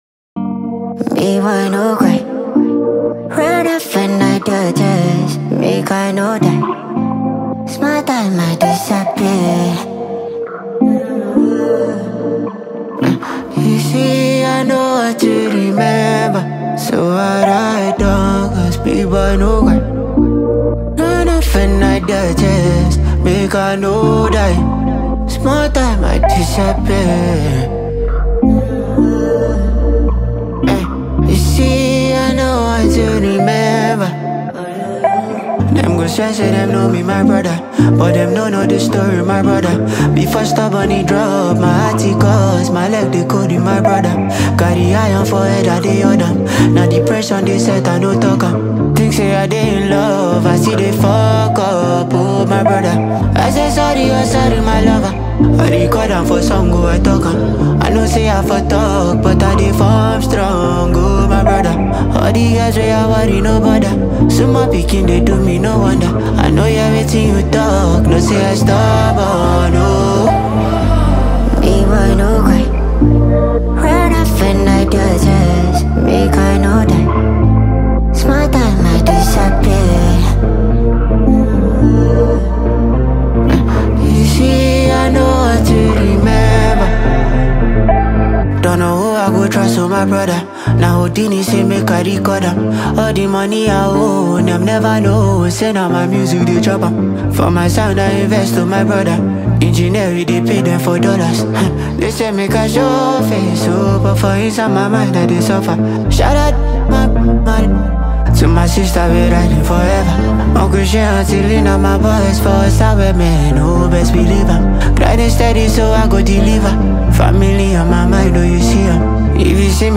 From smooth melodies to hard-hitting rhythms